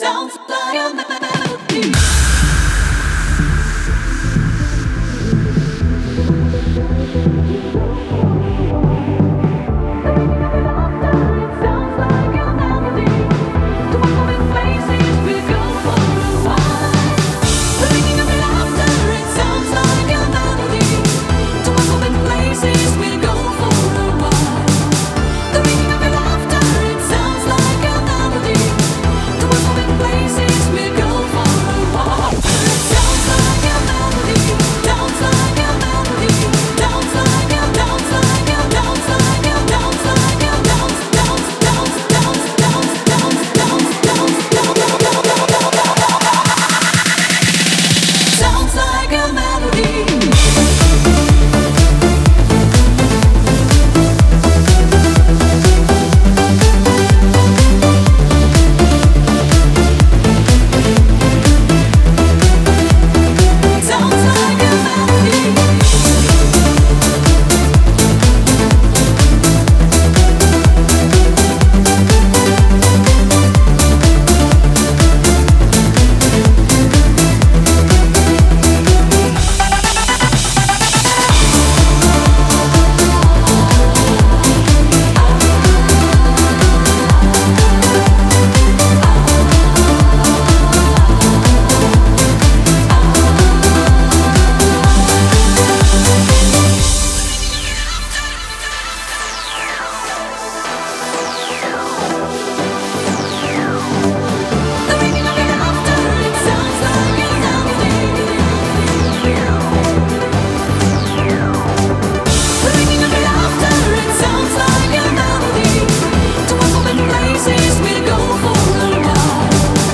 это яркий трек в жанре Eurodance